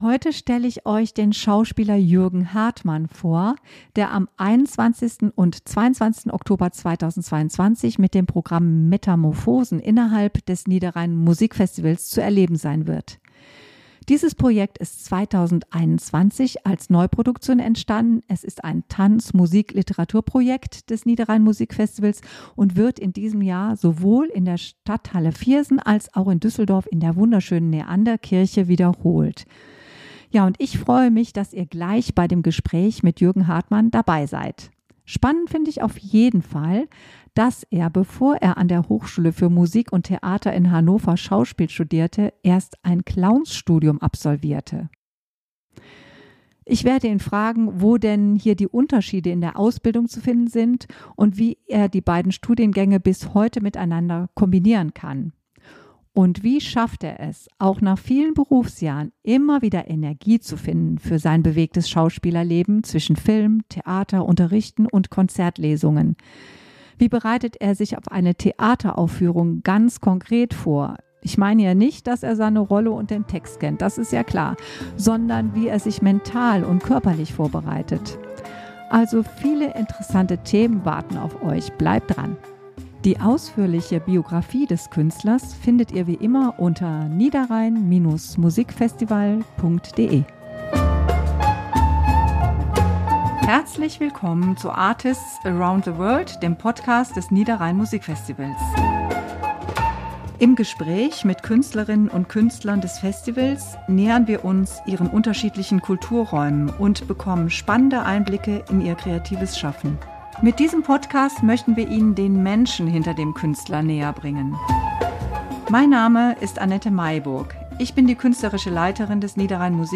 006 Wie man sein Herz bewahrt | Interview